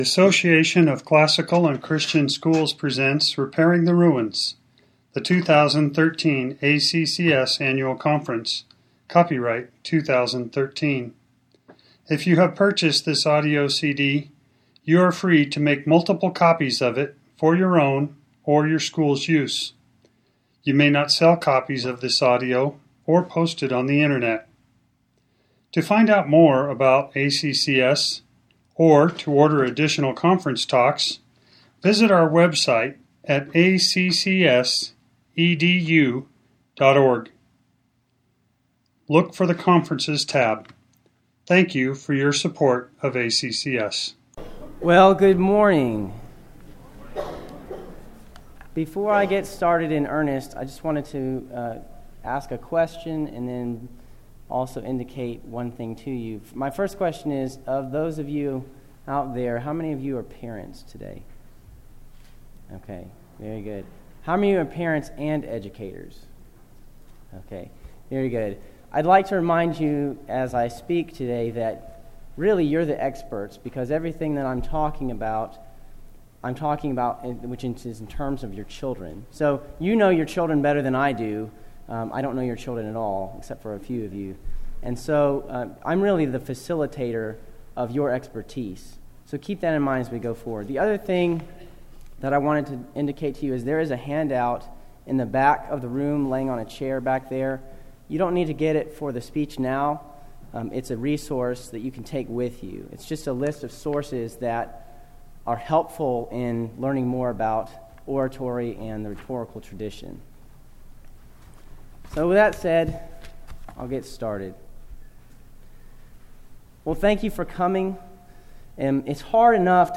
2013 Workshop Talk | 0:58:58 | All Grade Levels, Rhetoric & Composition